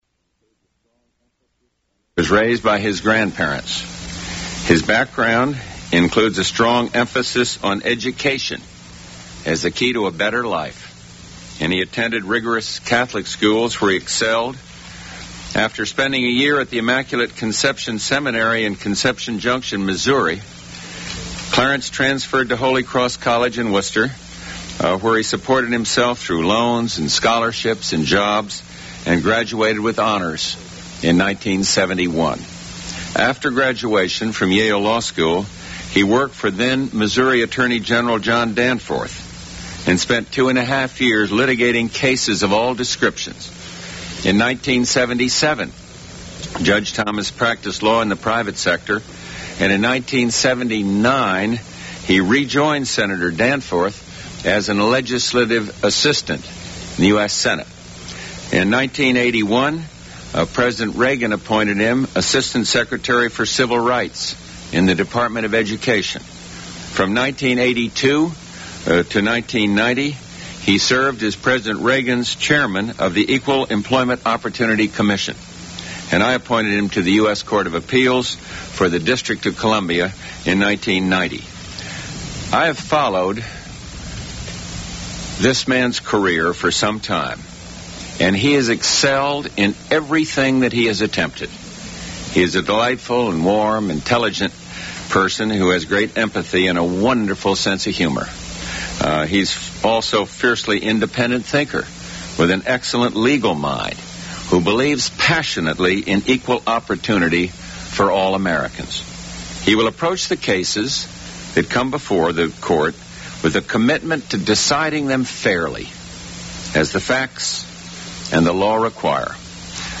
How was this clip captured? Supreme Court Judges--Selection and appointment Politics and government United States Material Type Sound recordings Language English Extent 00:49:03 Venue Note Broadcast 1991 July 1.